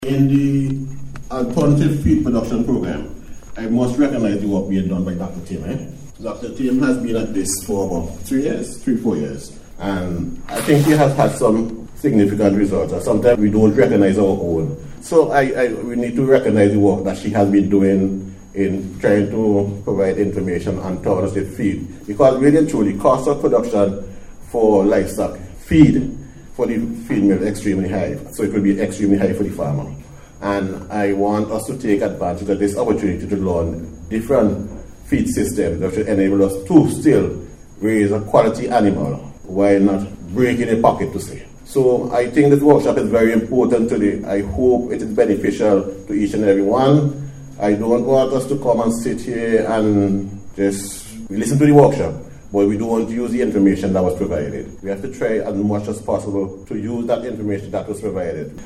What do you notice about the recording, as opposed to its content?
Speaking at the Opening Ceremony of the Workshop